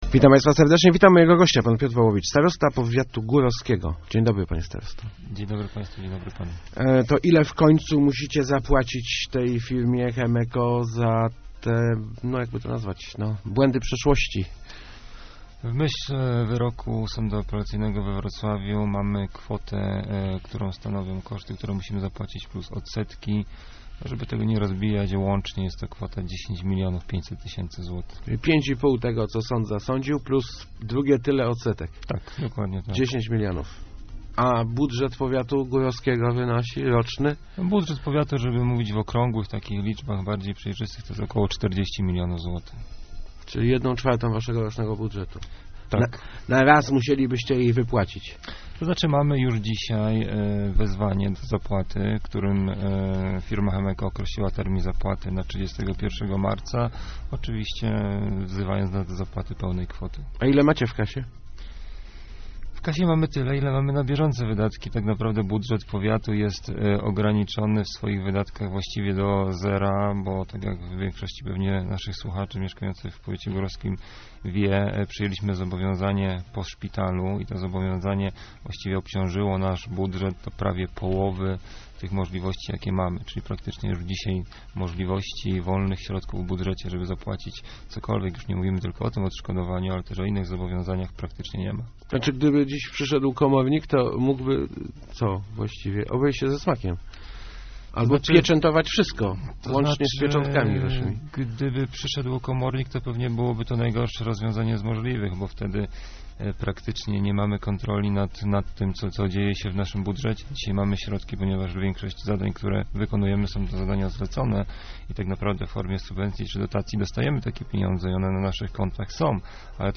Mieszkańcy powiatu mogą na kilka lat zapomnieć o inwestycjach - mówił w Rozmowach Elki starosta górowski Piotr Wołowicz. To skutek wyroku, na mocy którego powiat musi zapłacić spółce Chemeko 10 milionów złotych.